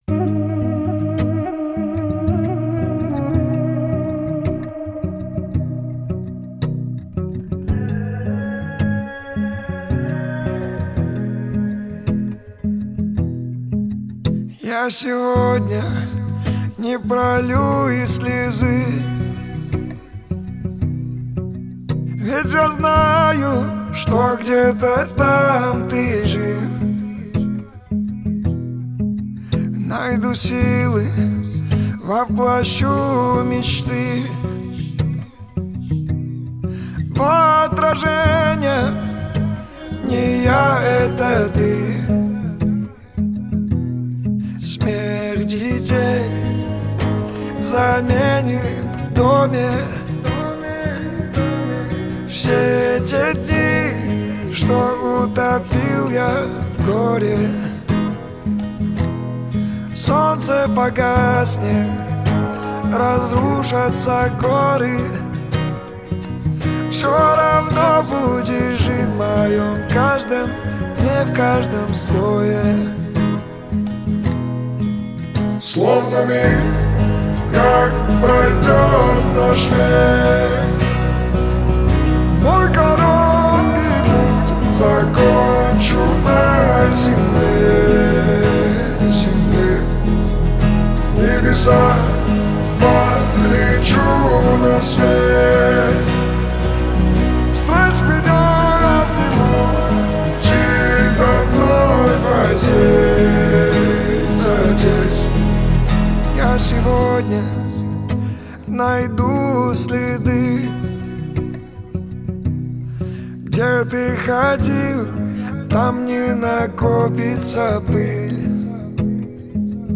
Трек размещён в разделе Русские песни / Поп.